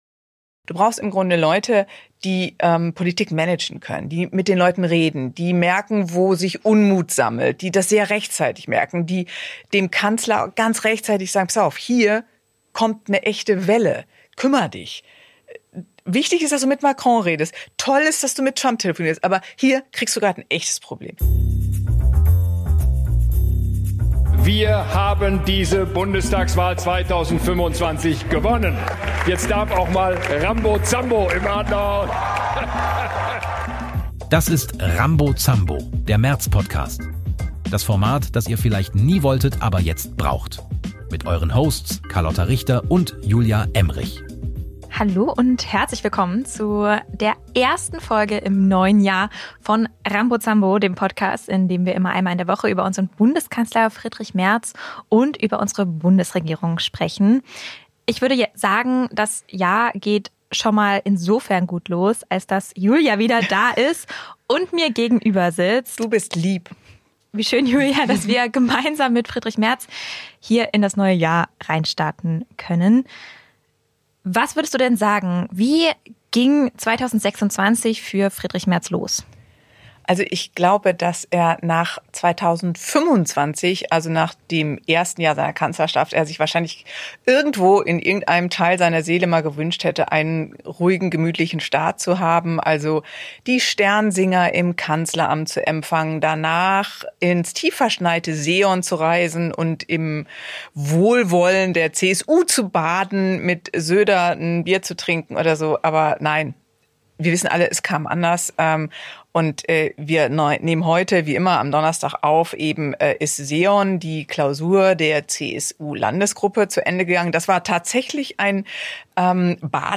Zwei Frauen. Ein Kanzler. Immer freitags.